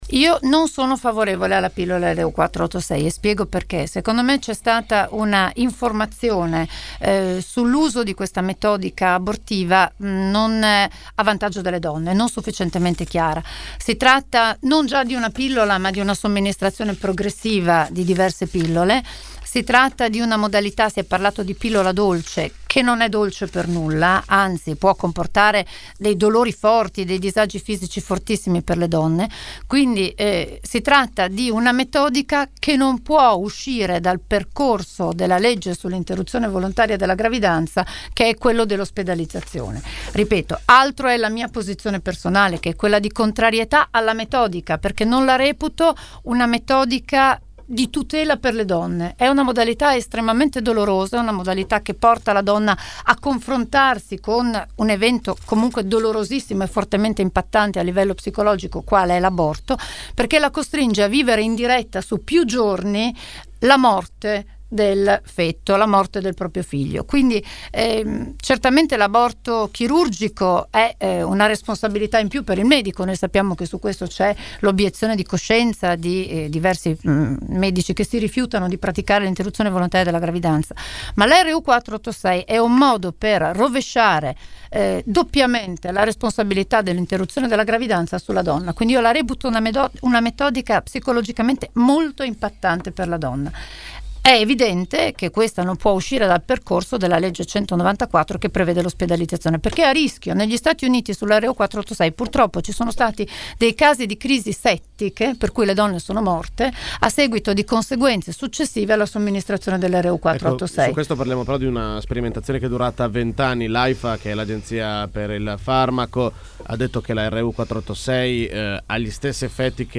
Ecco una sintesi dell’intervista andata in onda all’interno di Angolo B.